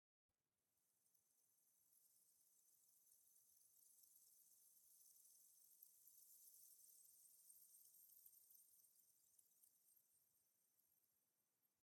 firefly_bush11.ogg